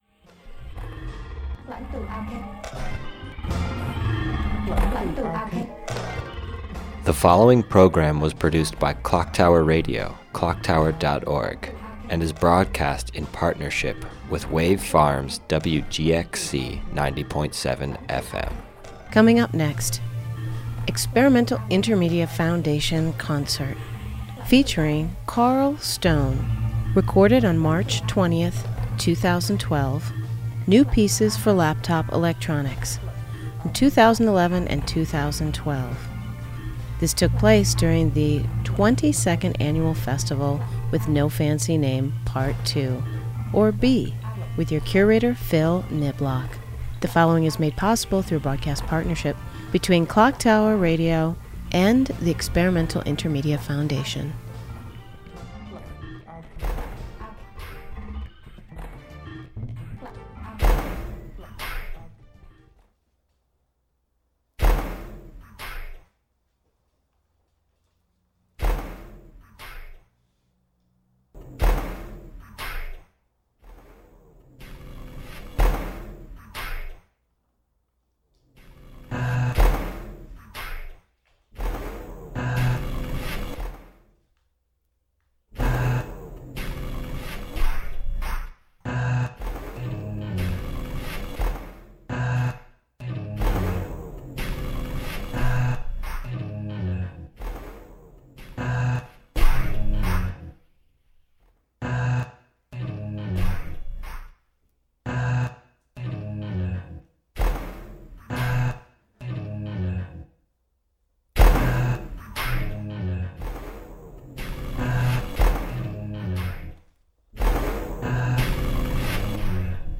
laptop electronics